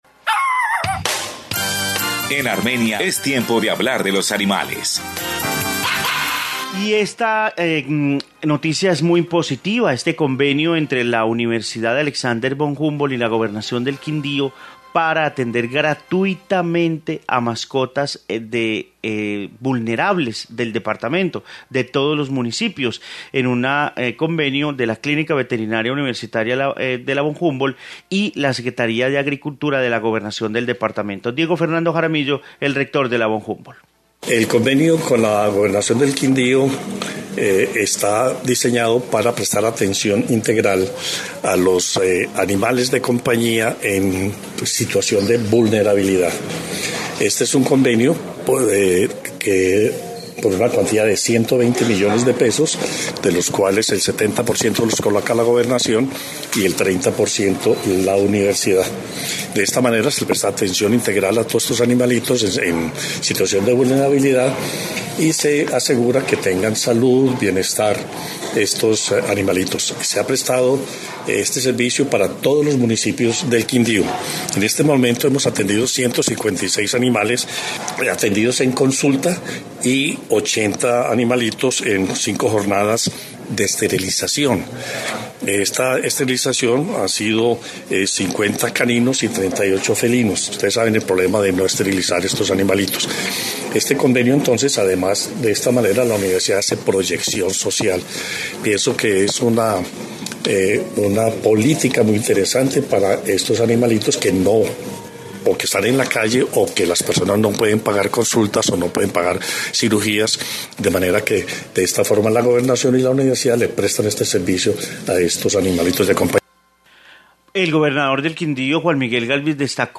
Informe convenio mascotas